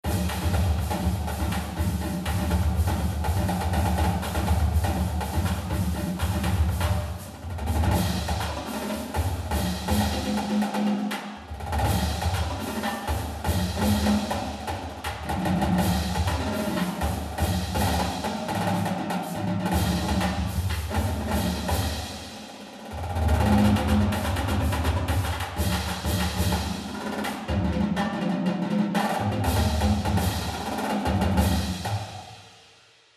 Marching Band Example